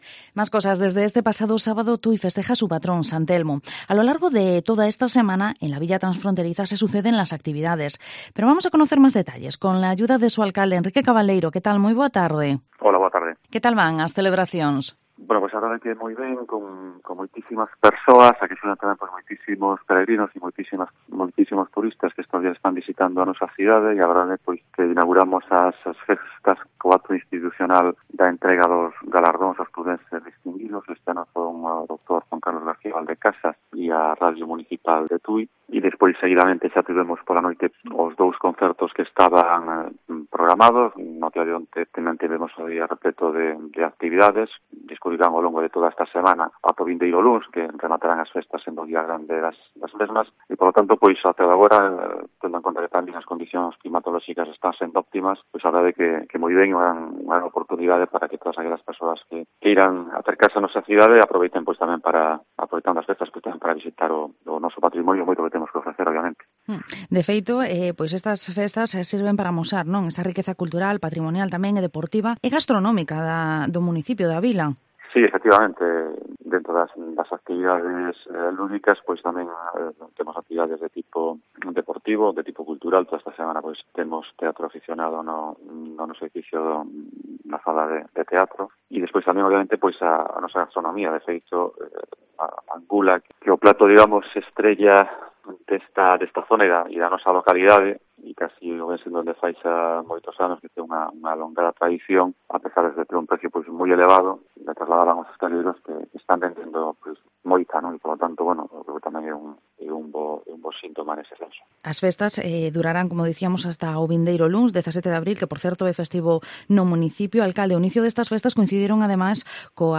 Entrevista al Alcalde de Tui, Enrique Cabaleiro